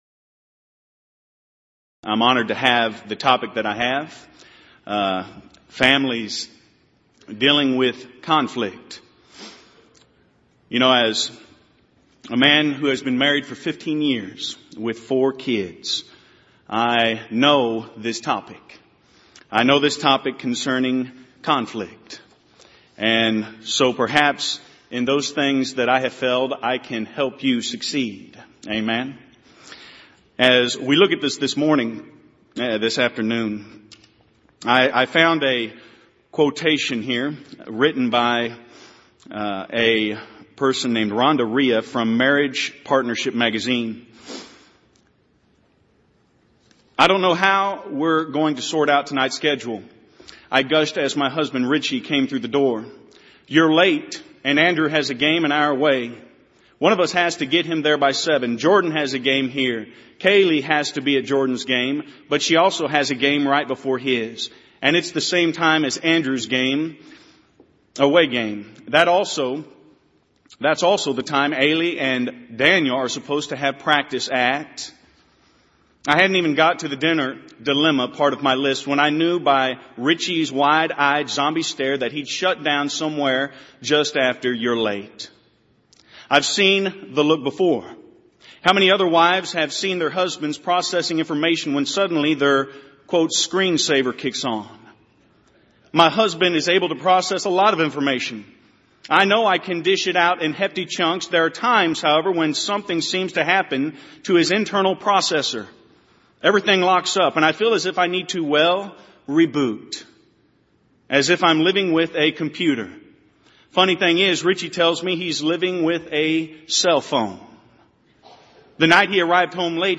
Event: 28th Annual Southwest Lectures
lecture